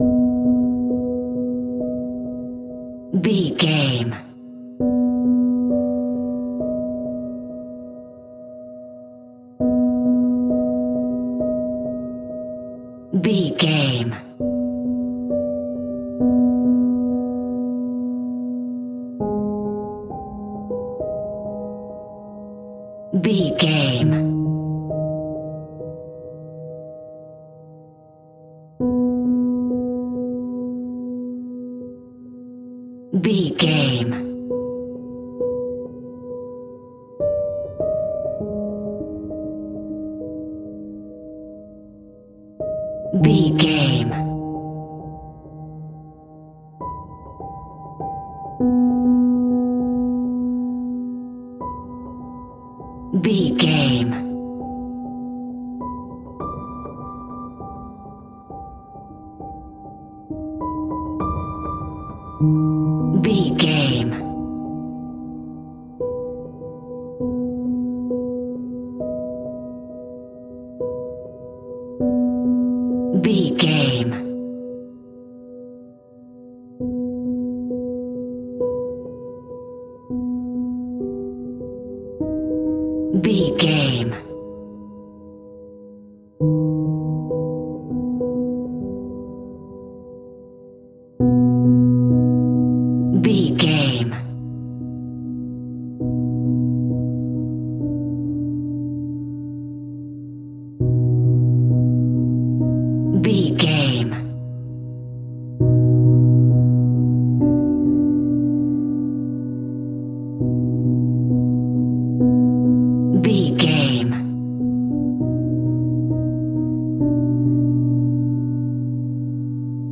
Aeolian/Minor
Slow
scary
ominous
dark
haunting
eerie
horror music
horror piano